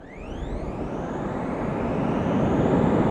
autopilotstart.ogg